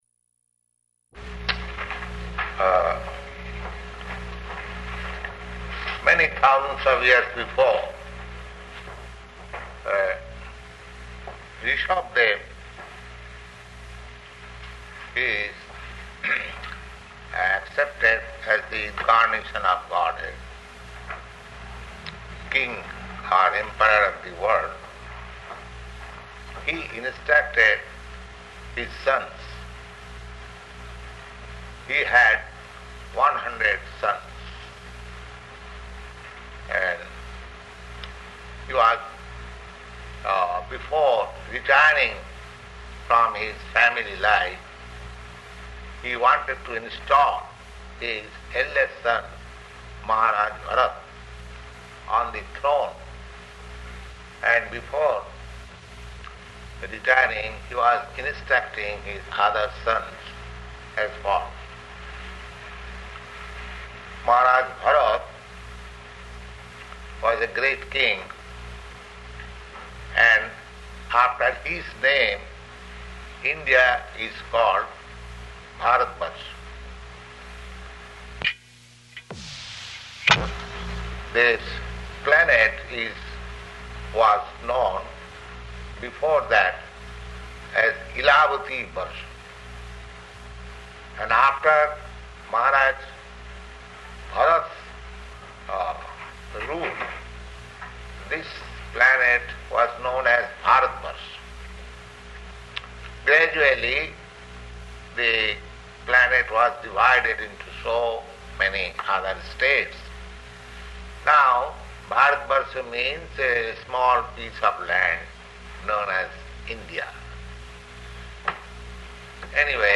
Śrīmad-Bhāgavatam 5.5.1–2 --:-- --:-- Type: Srimad-Bhagavatam Dated: September 13th 1969 Location: London Audio file: 690913SB-LONDON.mp3 Prabhupāda: ...many thousands of years before, Ṛṣabhadeva...